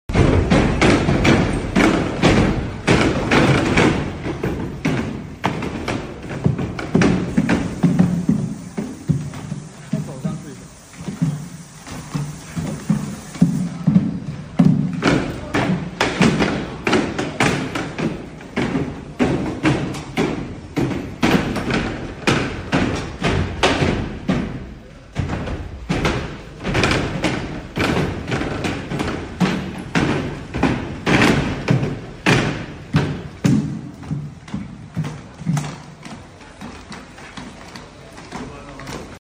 🔥🤖 250kg?! This Unitree robot sound effects free download